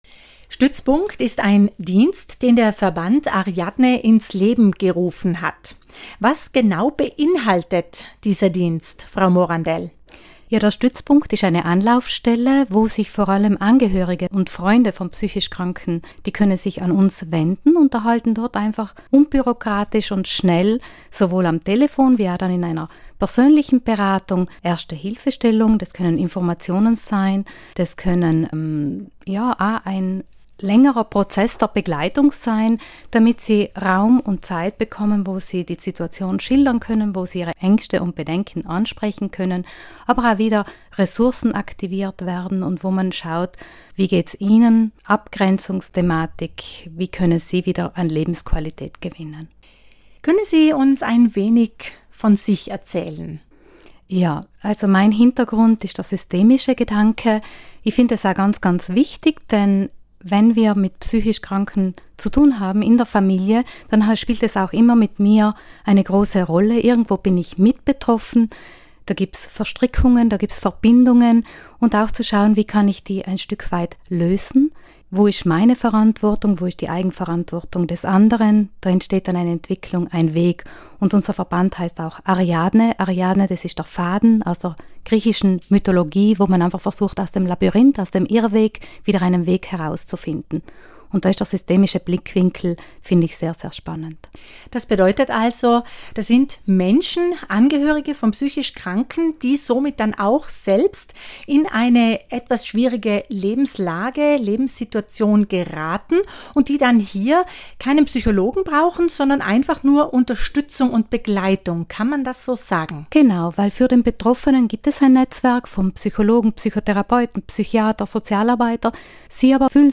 Radio-Grüne-Welle-Interview
Stützpunkt-Interview-RadioGrüneWelle-2017.mp3